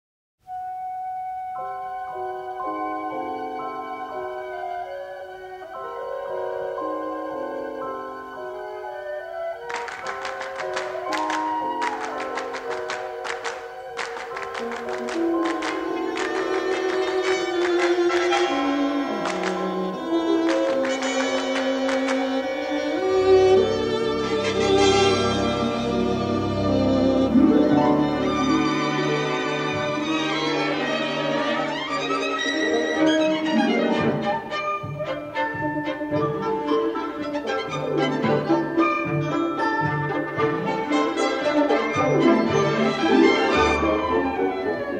in the best-possible monaural sound.